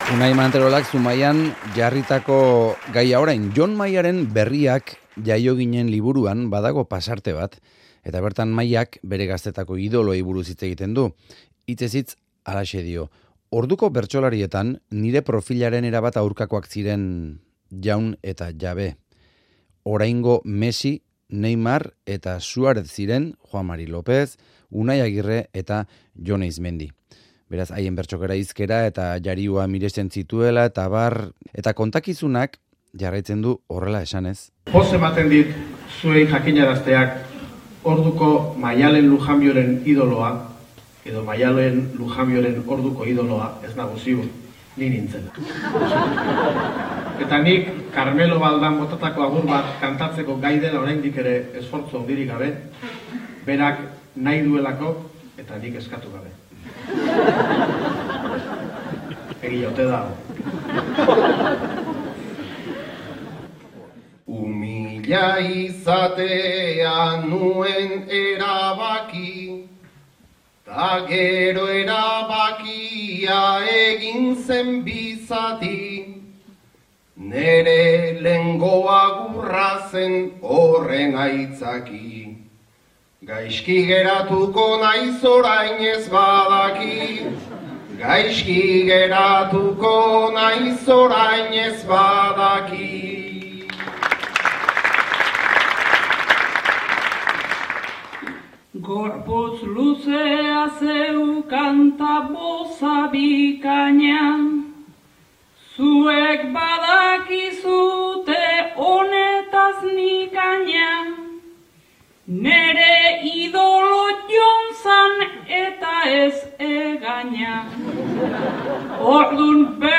Audioa: Zumaiako bertso jaialdian Jon Maia eta Maialen Lujanbio eskolarteko garaiei buruz. Maialenek Jonen orduko agur bat kantatu zuen goitik behera